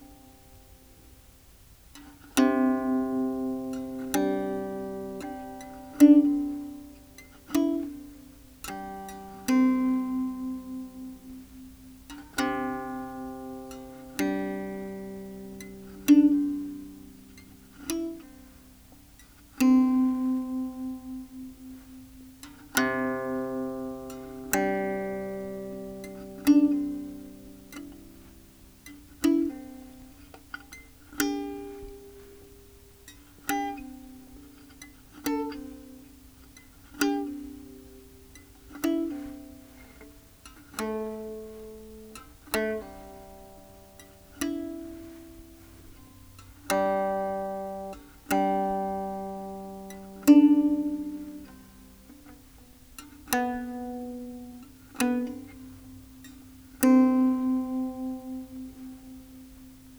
三味線・音源
駒：象牙（オリジナル）